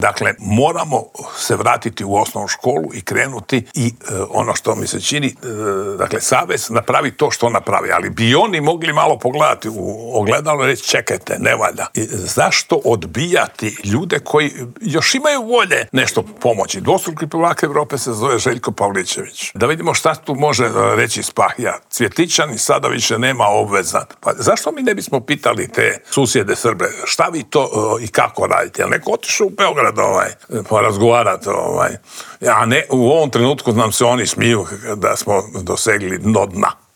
Kako sve krenulo prema dolje, pokušali smo odgonetnuti u Intervjuu tjedna Media servisa u kojem je gostovao bivši TV komentator, legendarni Slavko Cvitković.